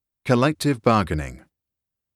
[kuh-lek-tiv] [bahr-guh-ning]